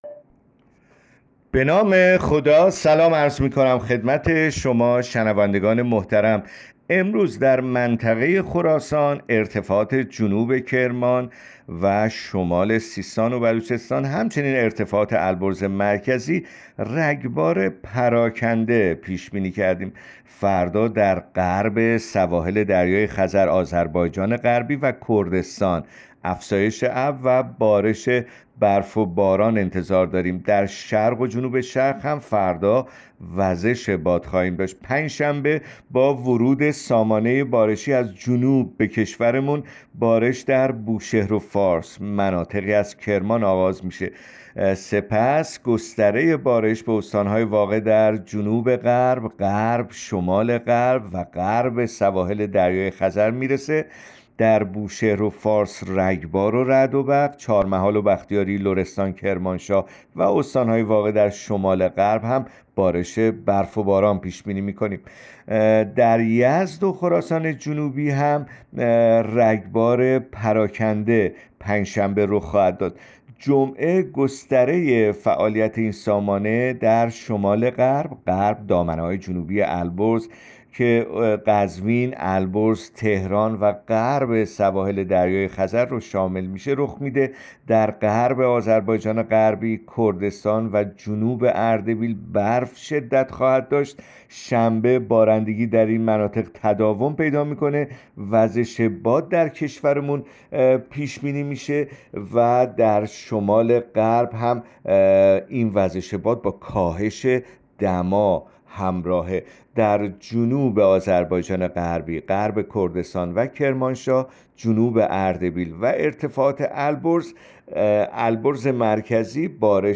گزارش رادیو اینترنتی پایگاه‌ خبری از آخرین وضعیت آب‌وهوای ۳۰ بهمن؛